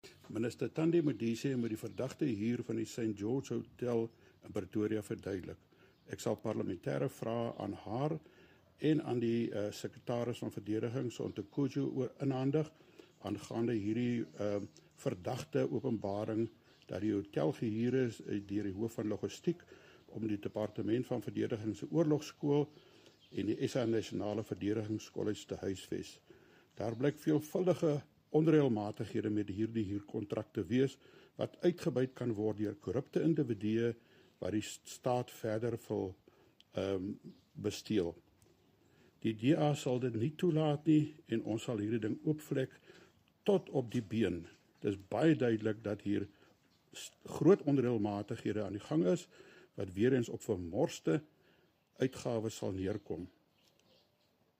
Afrikaans soundbites by Kobus Marais MP.